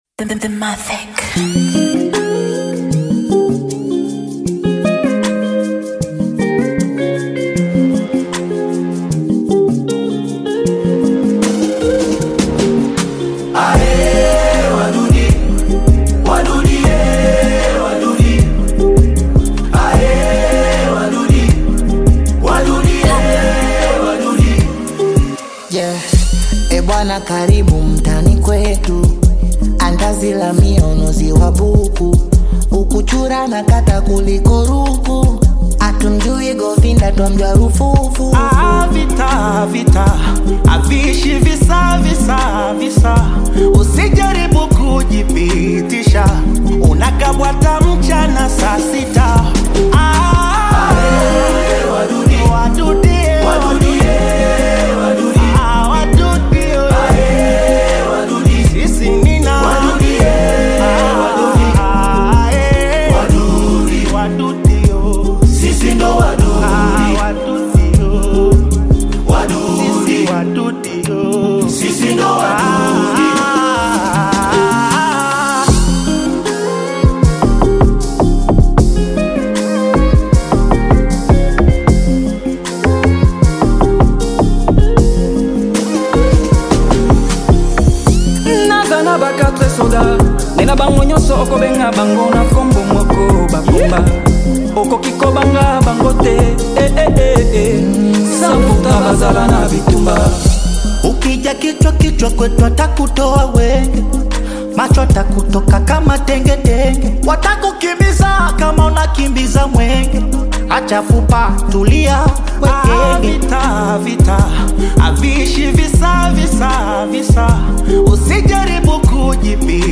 Bongo Flava and Afrobeat styles